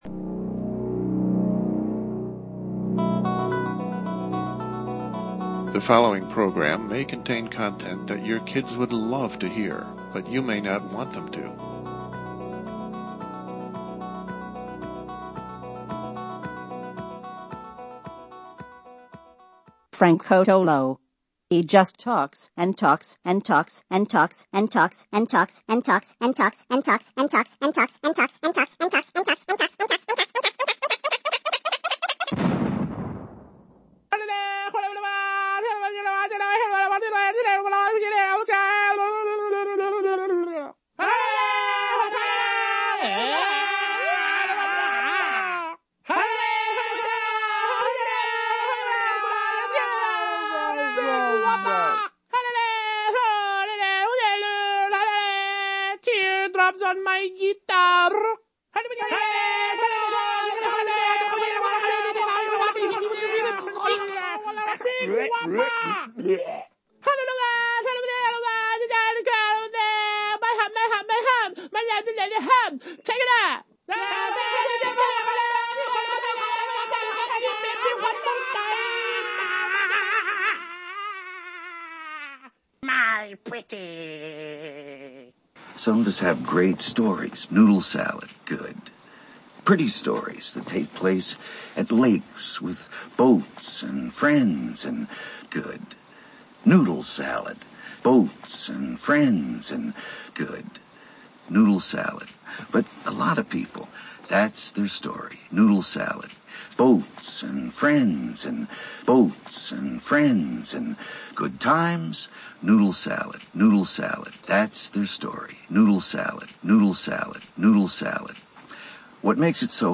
As February ends, we talk under the cape of anonymity to a member of the younger generation about the lifestyle of a generation weaned on digital platforms. This is exclusive and LIVE.